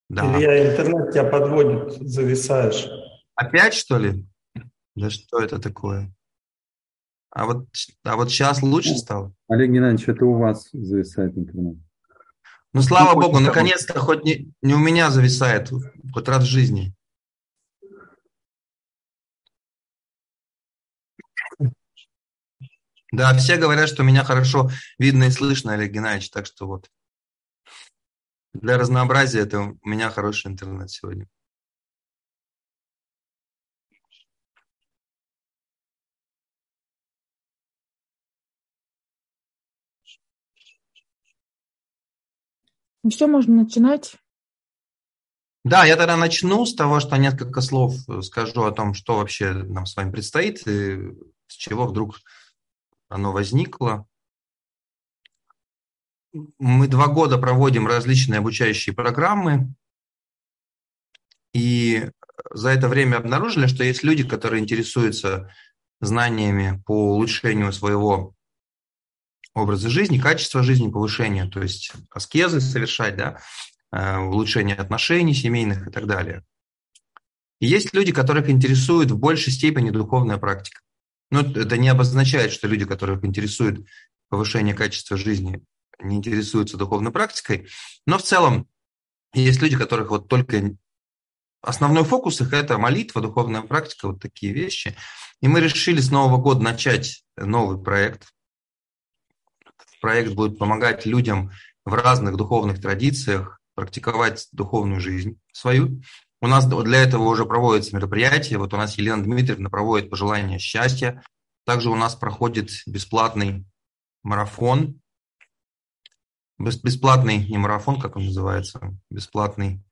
Рождение Господа в твоем сердце (вебинар, 2023) - Официальный сайт Олега Геннадьевича Торсунова